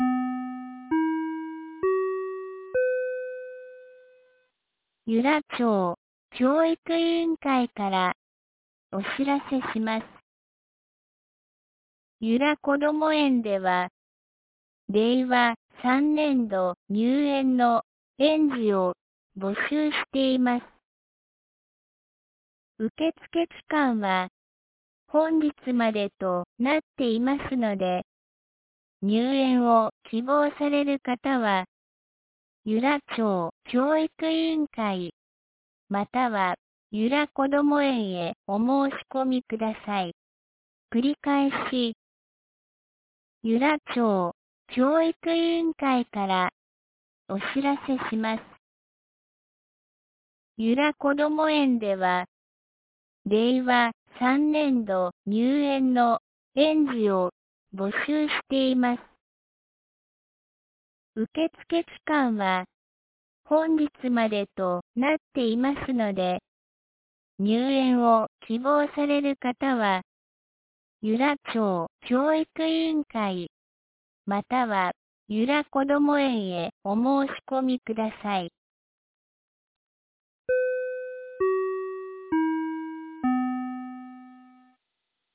2020年10月26日 12時21分に、由良町から全地区へ放送がありました。